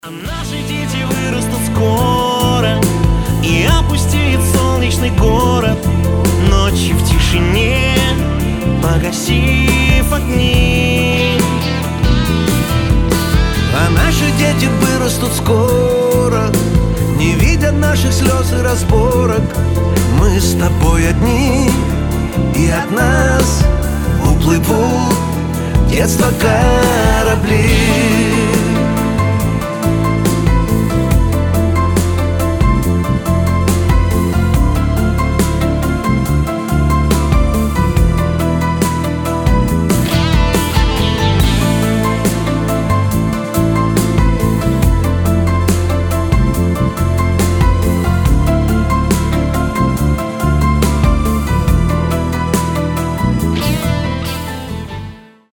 Нежные рингтоны , Грустные
Дуэт , Pop rock , Мелодичные